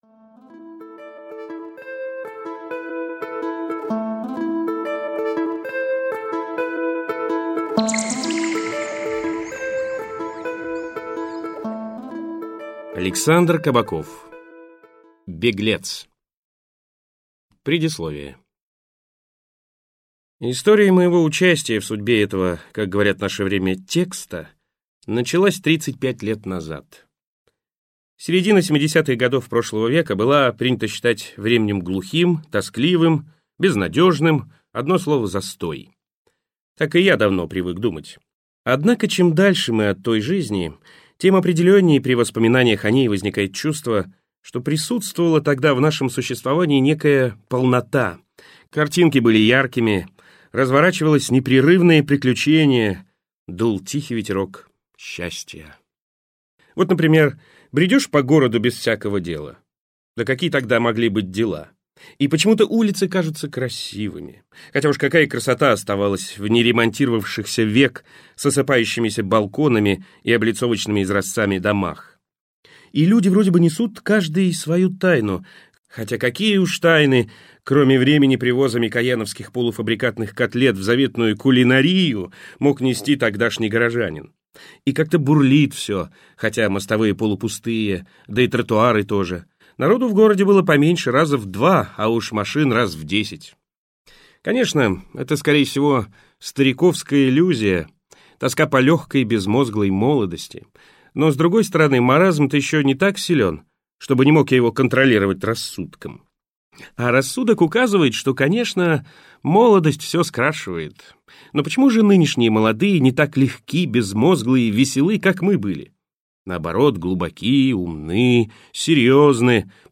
Аудиокнига Беглецъ | Библиотека аудиокниг